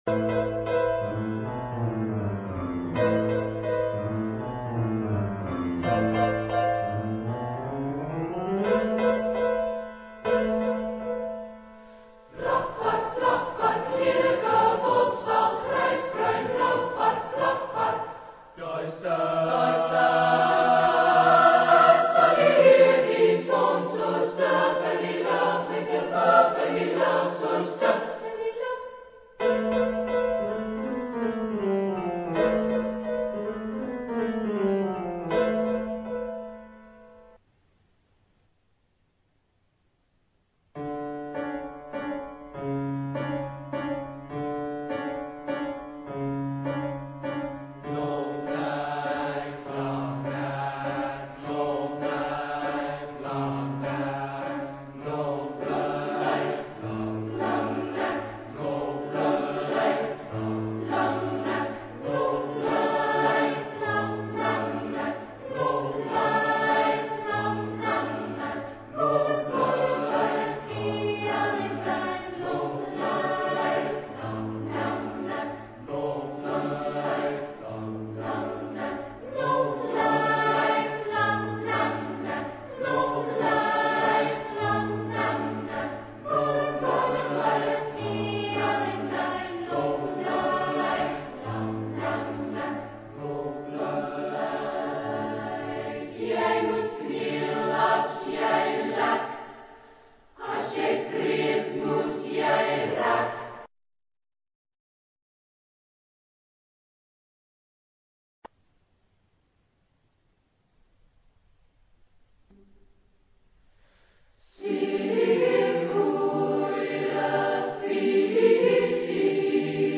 Carácter de la pieza : rítmico ; humorístico ; satírico
Tipo de formación coral: SSATB  (5 voces Coro mixto )
Instrumentación: Piano  (1 partes instrumentales)
Tonalidad : sol mayor ; re mayor ; la menor